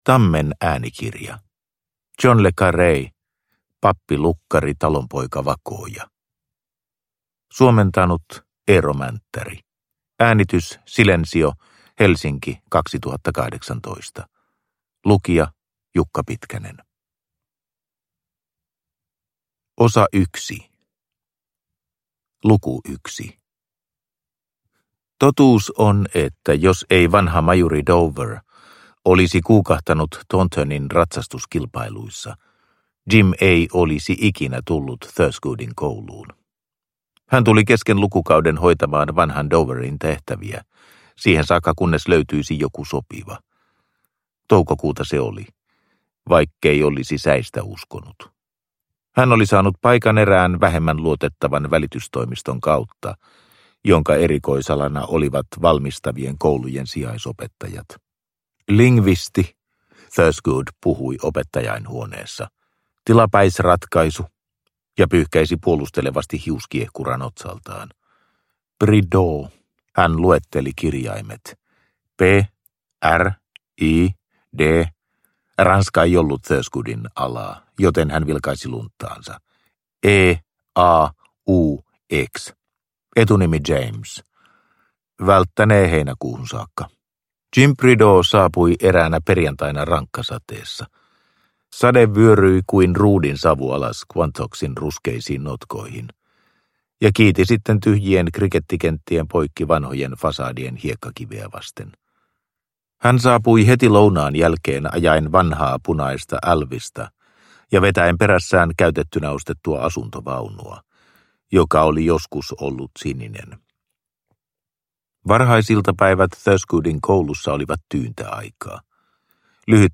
Pappi, lukkari, talonpoika, vakooja – Ljudbok – Laddas ner